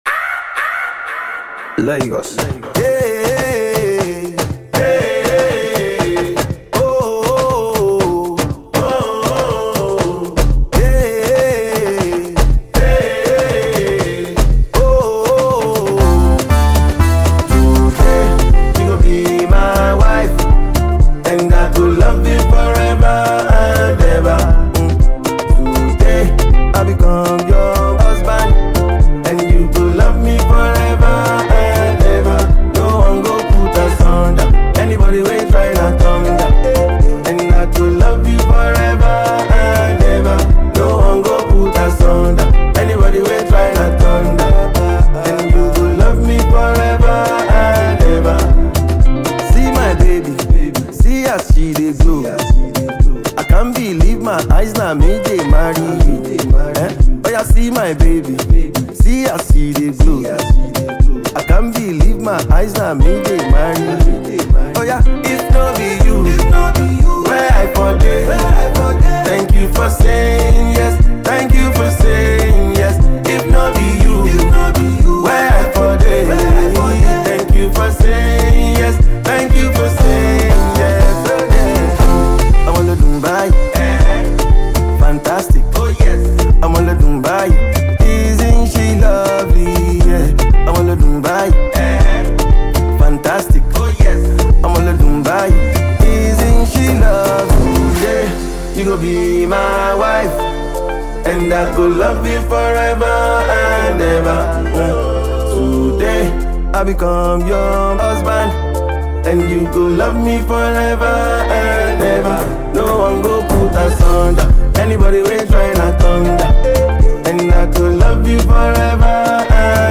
” a heartfelt love song that celebrates commitment
Known for his smooth vocals and romantic storytelling
blends soulful melodies with relatable lyrics